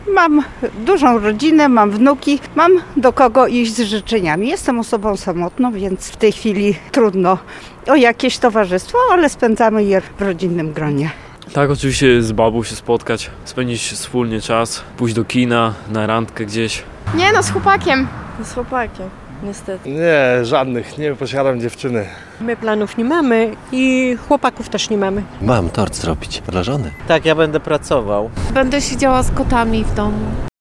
Takie plany na Walentynki mają przechodnie, zapytani na ulicach Suwałk.